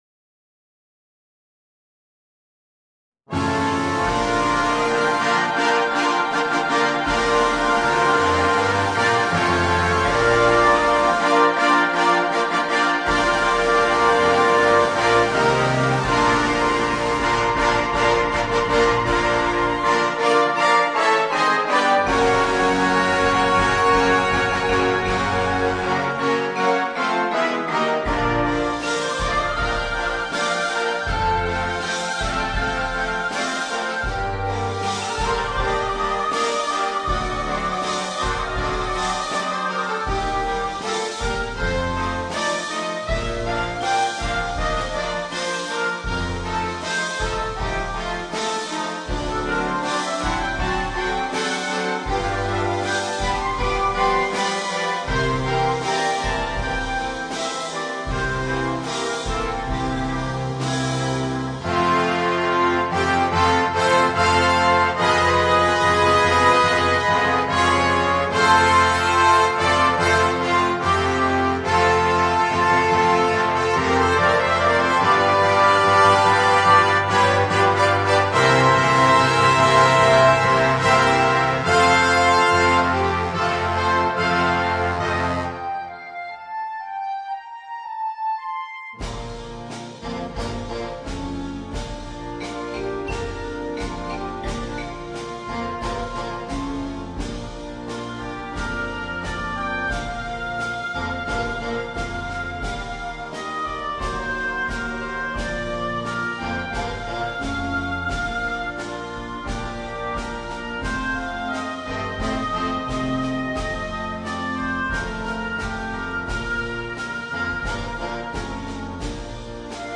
Un brano da concerto moderno, piacevole e d’effetto.
MUSICA PER BANDA